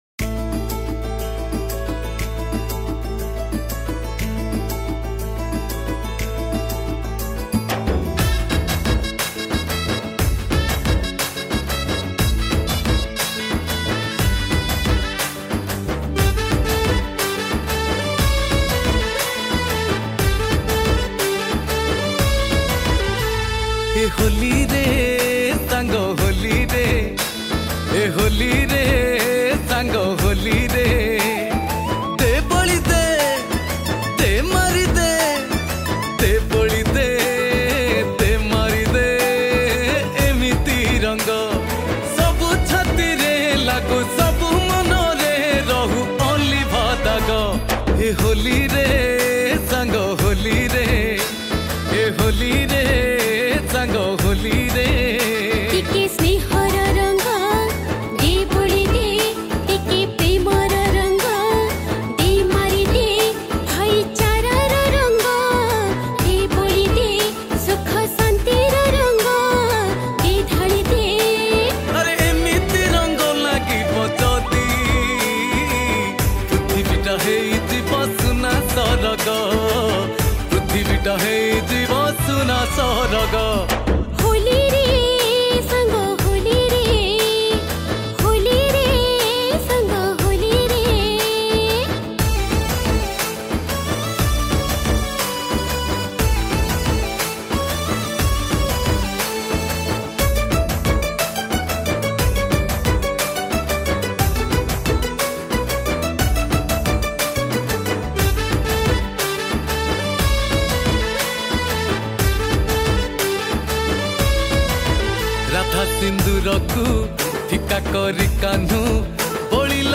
Holi Spcial Mp3 Song Songs Download